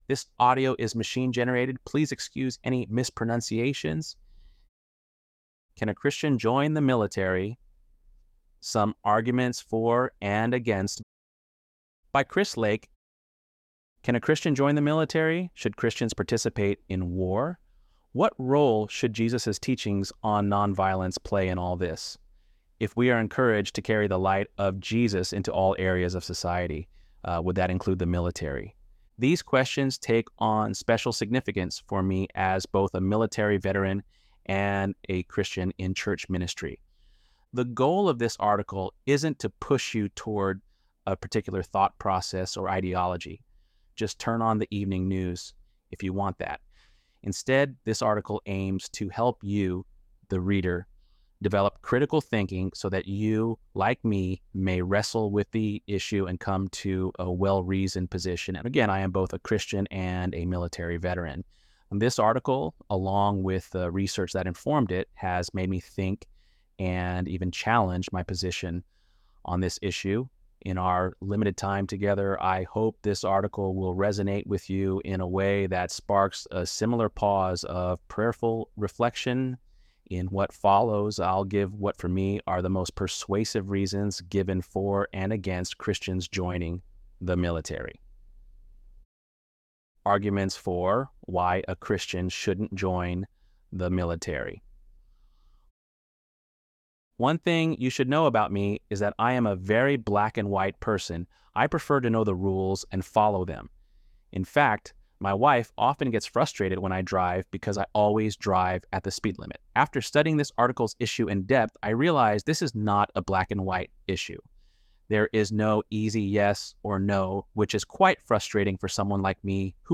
ElevenLabs_1_7.mp3